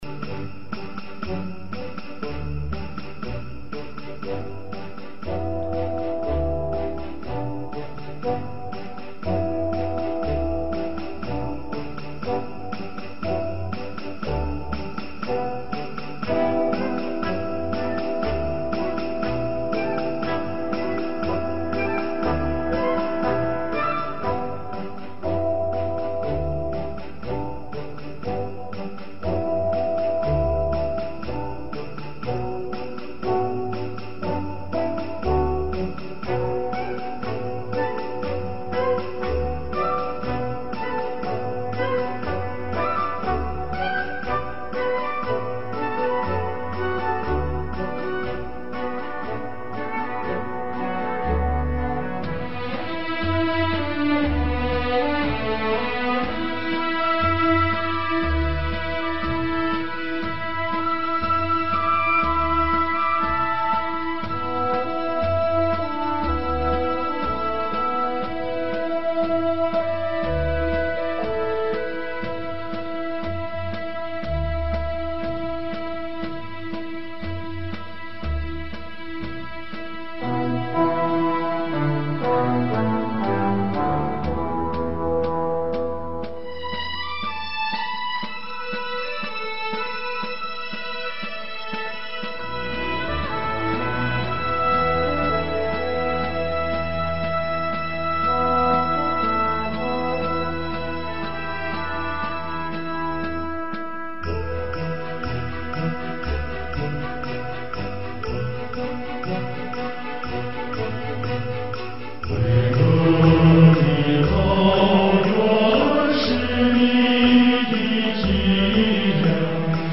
旋律优美动听，男声刚劲有力。格调高雅大气，录音丰满，是一首难得的音乐作品。